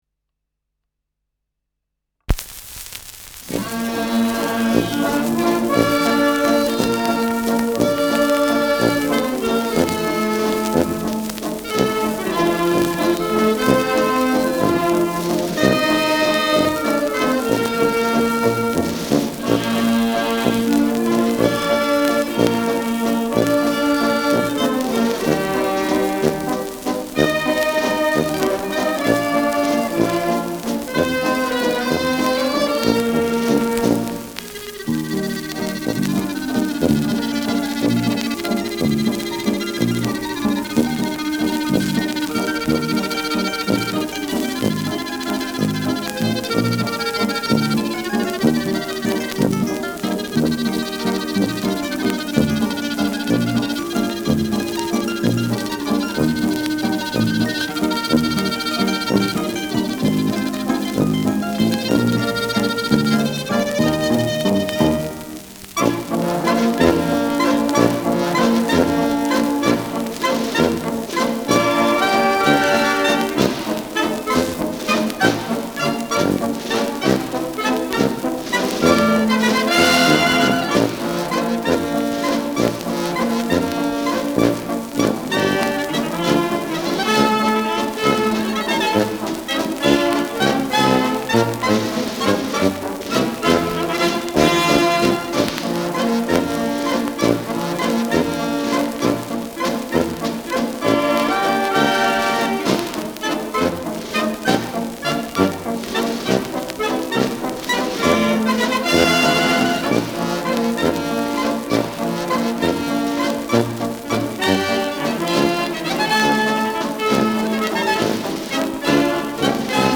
Schellackplatte
leichtes Rauschen : Knistern
Bei dieser Aufnahme klingt im zweiten Teil sehr wahrscheinlich eine Klarinette, die in den Schalltrichter eines Blechblasinstrumentes gespielt hat, wobei der Blechblasinstrumentenspieler mit den Ventilen das Tremolo erzeugt hat.
[Berlin] (Aufnahmeort)